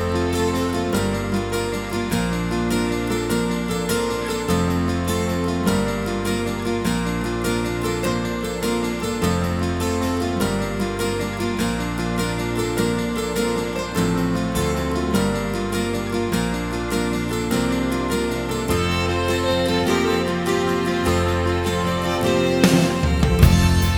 No Backing Vocals Irish 3:54 Buy £1.50